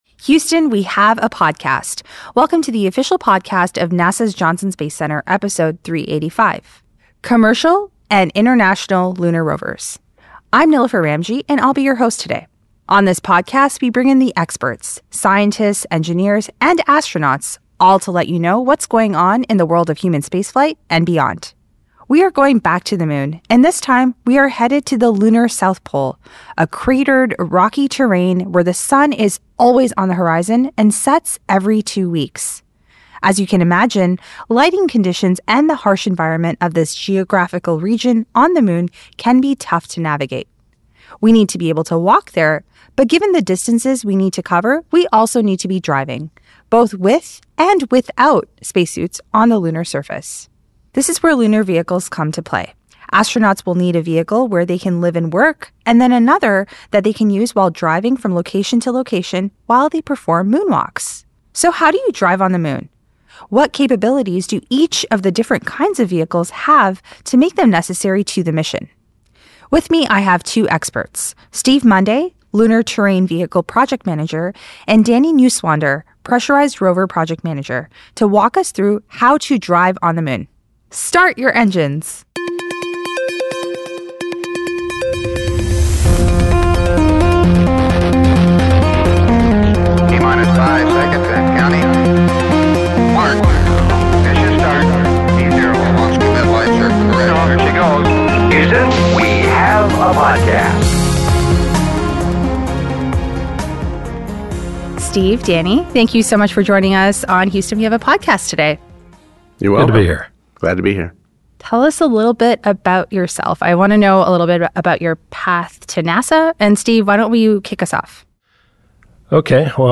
Two NASA project managers discuss how astronauts will drive on the lunar surface during future Moon missions.